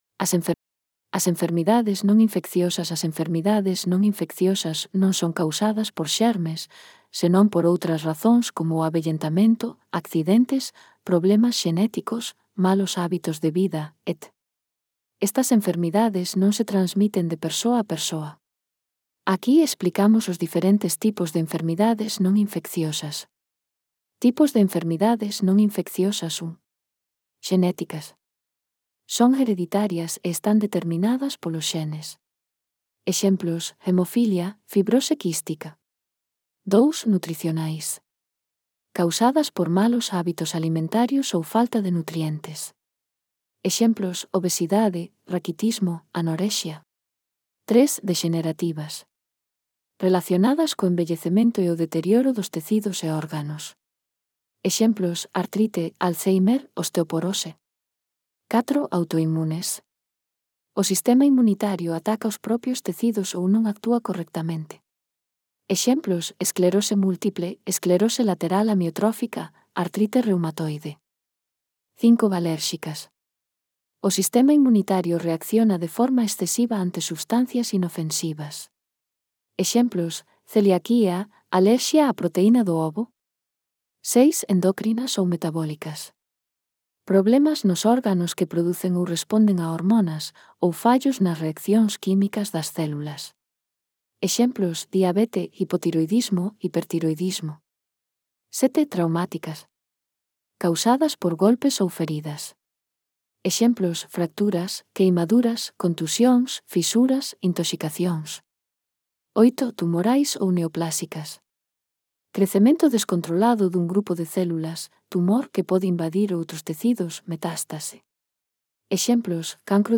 Lectura facilitada
Elaboración propia. Transcrición a audio do texto sobre enfermidades infecciosas (CC BY-SA)